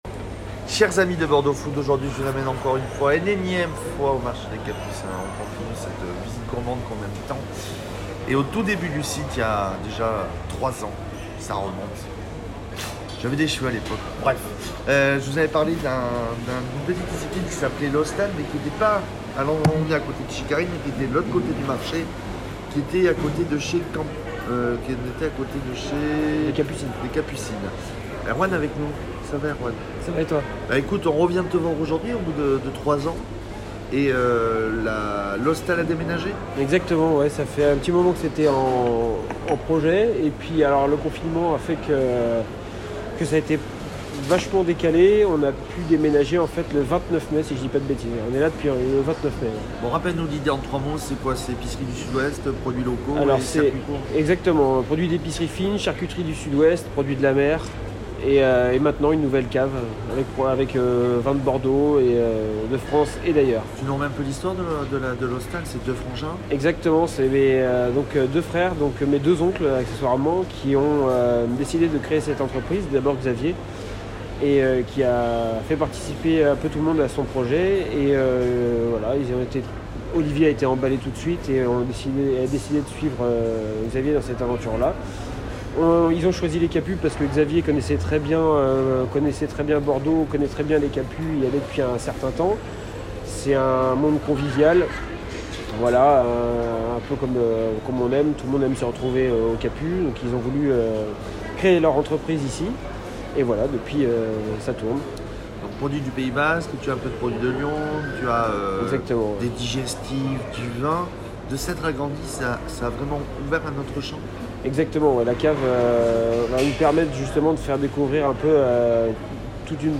Direction le marché des Capucins pour en savoir plus et découvrir ou redécouvrir le lieu.